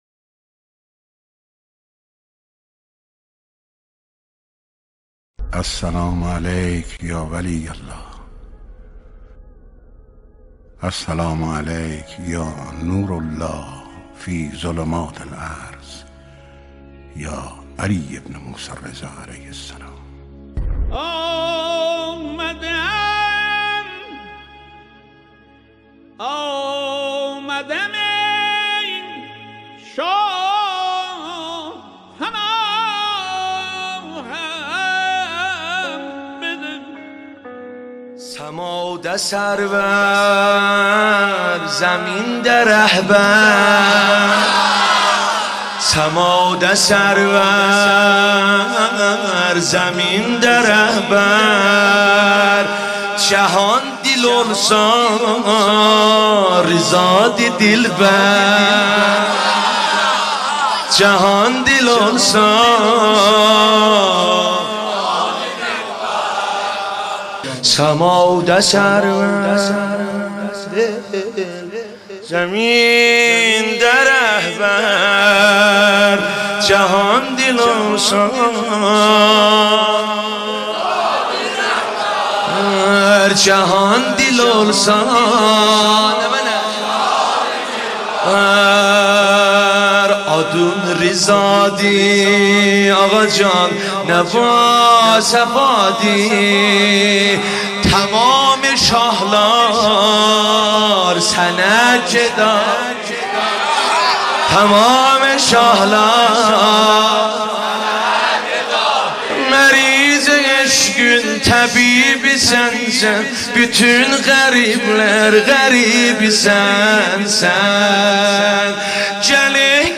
گلچین مولودی میلاد امام رضا علیه السلام